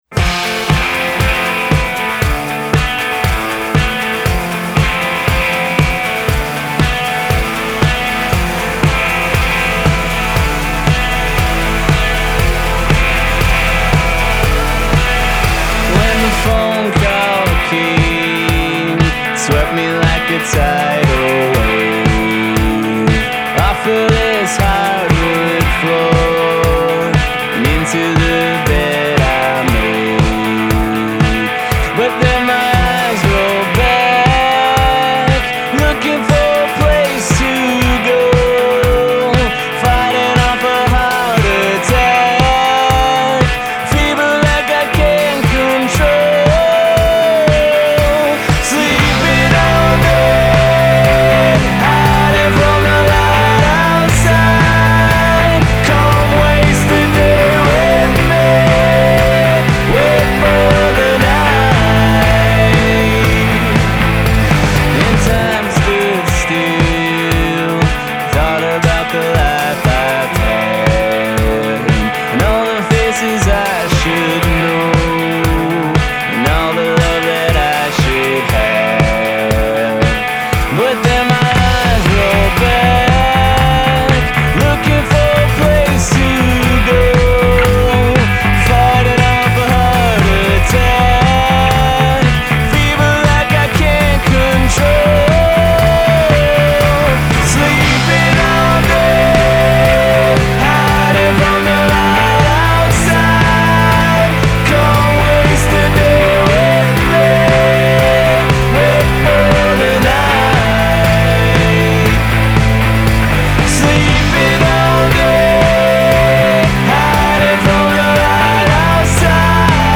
crunchy & unresolved